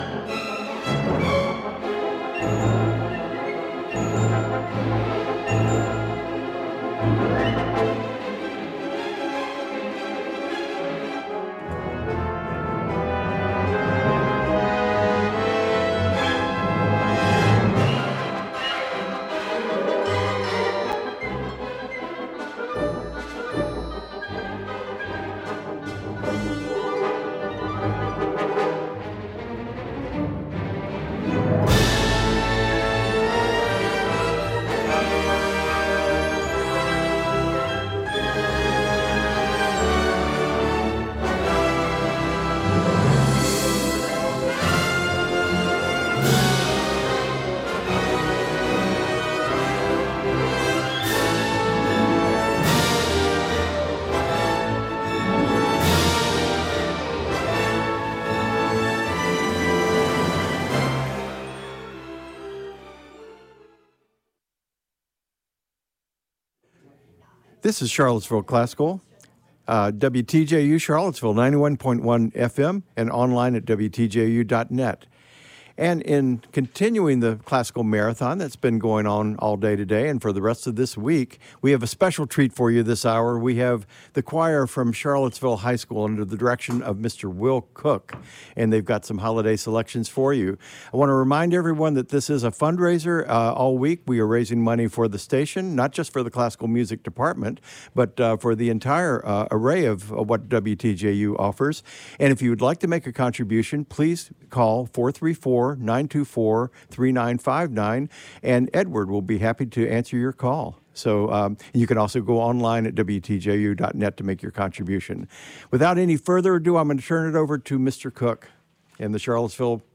The choir has also been presenting at events such as the UVA Hospital Tree Lighting and live on the radio as part of WTJU's Classical Marathon.